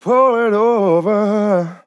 Categories: Vocals Tags: DISCO VIBES, dry, english, it, LYRICS, male, on, Pour, sample
man-disco-vocal-fills-120BPM-Fm-10.wav